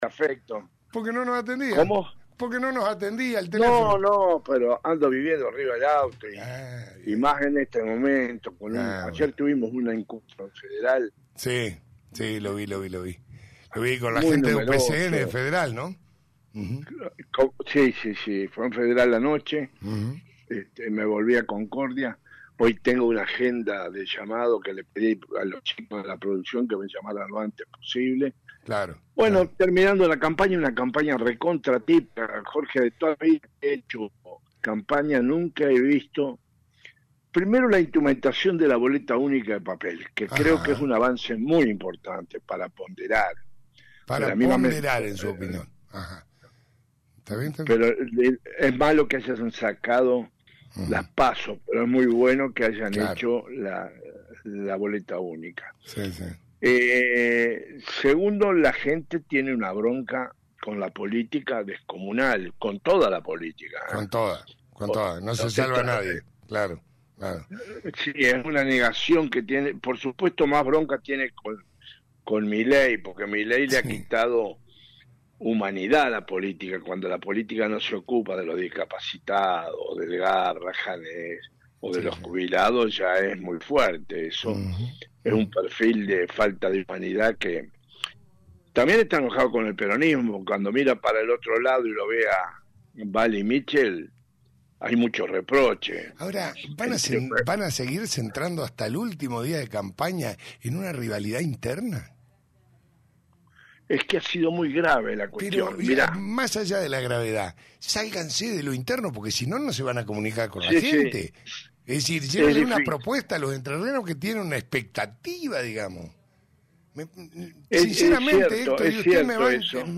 Entrevista a Héctor Maya, candidato a senador nacional por Entrerrianos Unidos.